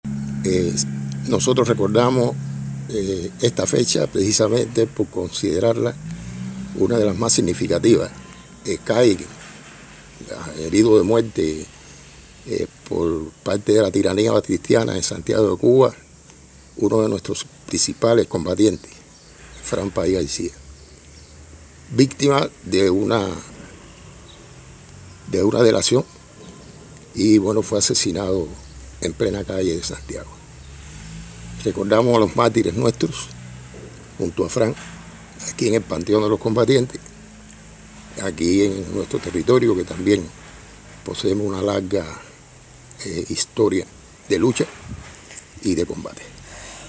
DECLARACION-1mp3.mp3